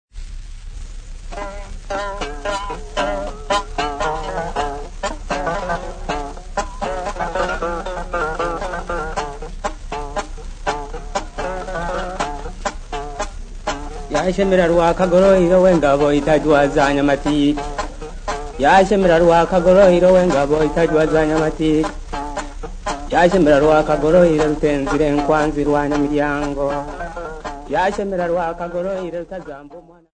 Dance music
Field recordings
Africa Tanzania Bukoba f-sa
A recitative Legend accompanied by the Nanga trough zither with 7 notes